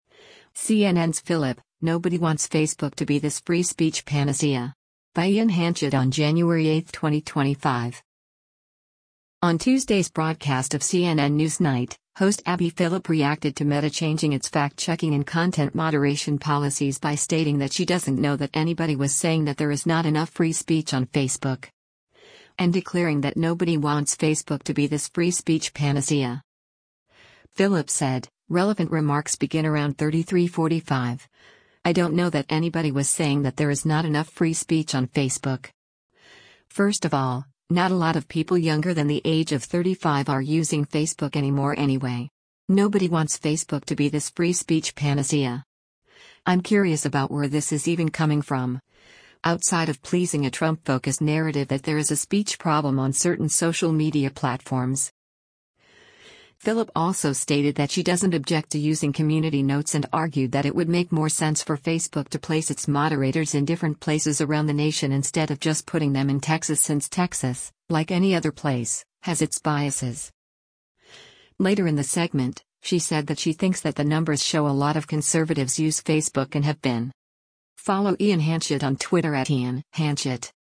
On Tuesday’s broadcast of “CNN NewsNight,” host Abby Phillip reacted to Meta changing its fact-checking and content moderation policies by stating that she doesn’t know “that anybody was saying that there is not enough free speech on Facebook.”